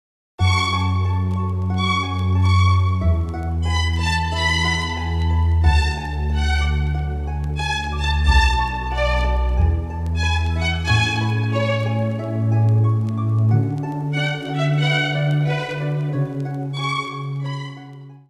Спокойные рингтоны , Рингтоны без слов , Нежные рингтоны
скрипка
crossover